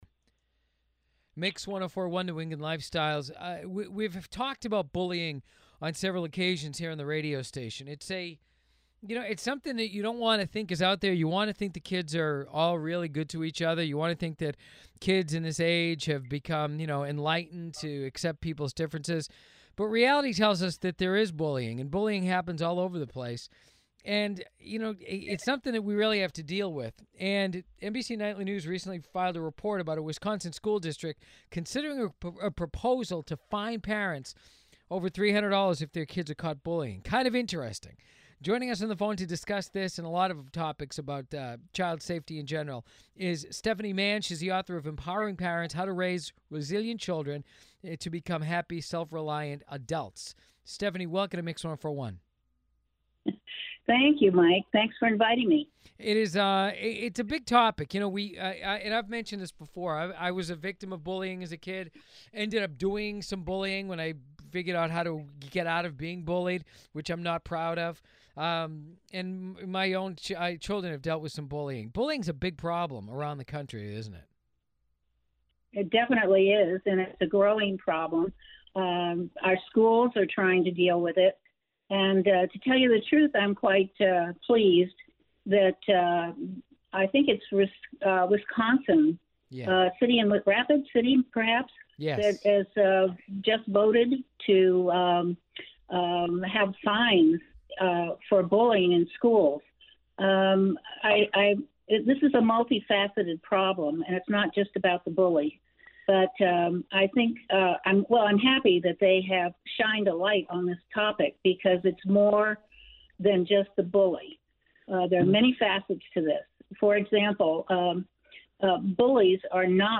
The interview will air on June 23, 2019 on WWBX Mix 104-1 in Boston between 6 am – 7 am. Listen to the interview